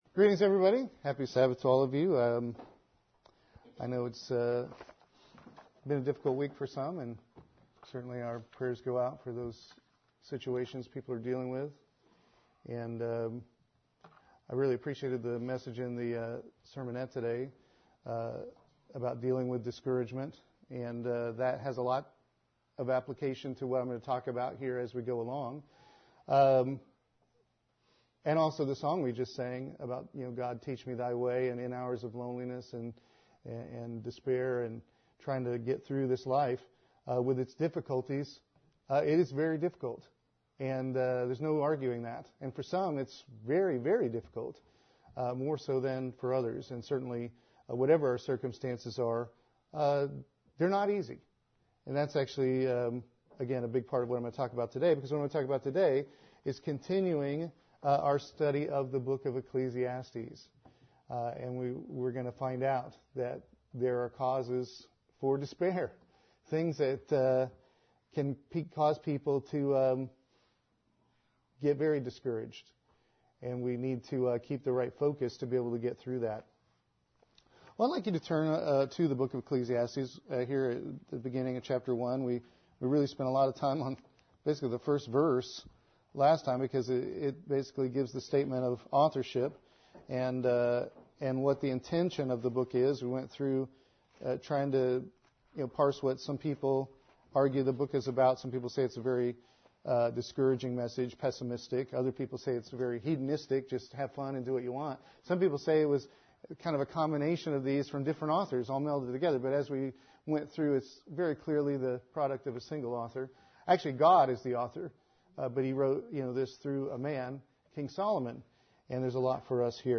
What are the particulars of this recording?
Given in Columbia - Fulton, MO